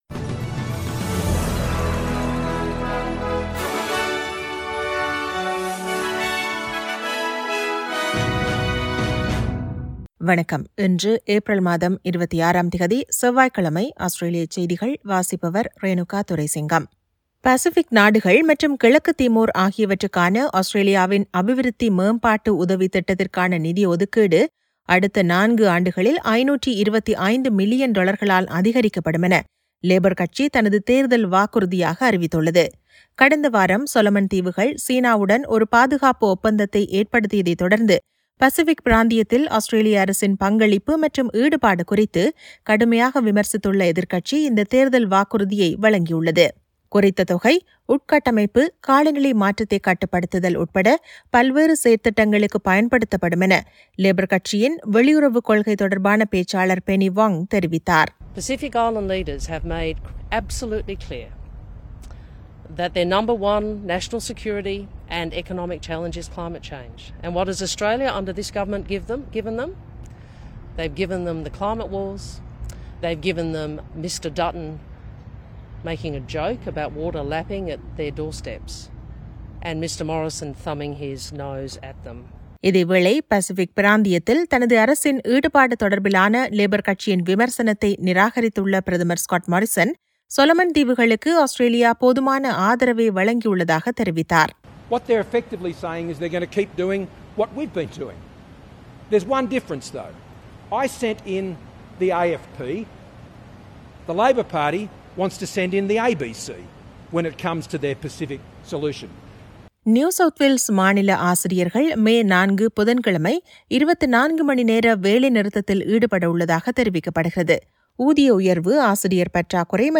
Australian news bulletin for Tuesday 26 April 2022.